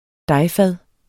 Udtale [ ˈdɑj- ]